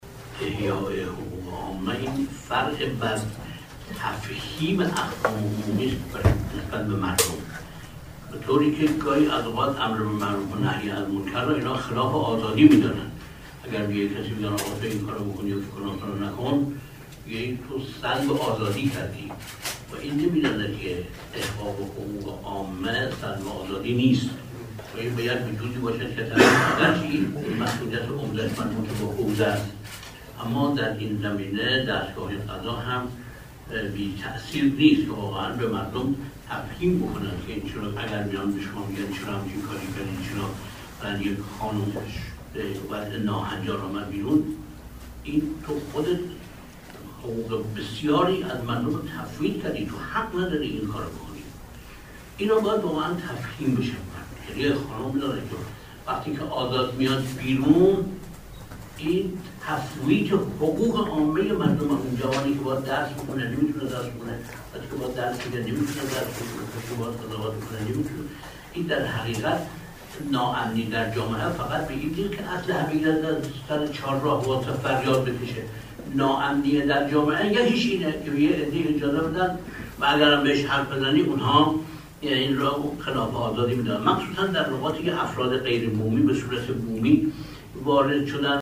به گزارش خبرنگار سیاسی خبرگزاری رسا، آیت الله حسن ممدوحی عضو جامعه مدرسین حوزه علمیه قم ظهر در نشست هفتگی جامعه مدرسین قم که با حضور مسؤولان قضایی قم برگزار شد، با بیان اینکه قانون احیای حقوق عامه باید برای مردم تفهیم شود، گفت: برخی امر به معروف و نهی از منکر را سلب آزادی خویش توصیف می کنند.